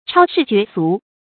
超世絕俗 注音： ㄔㄠ ㄕㄧˋ ㄐㄩㄝˊ ㄙㄨˊ 讀音讀法： 意思解釋： 見「超俗絕世」。